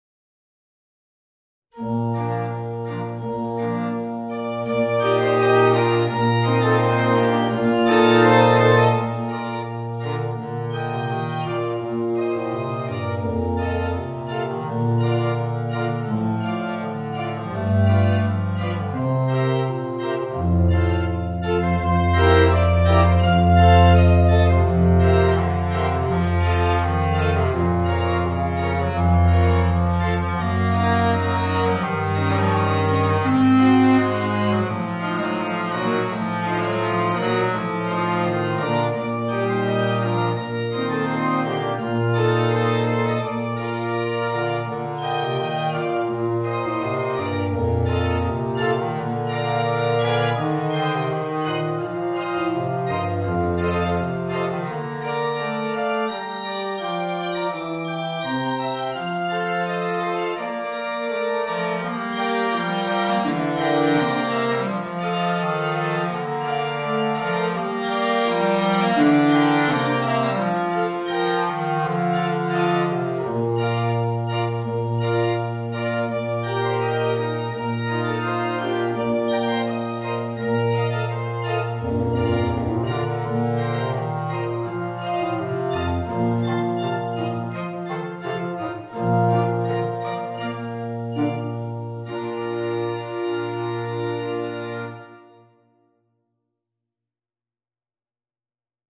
Bassklarinette & Klavier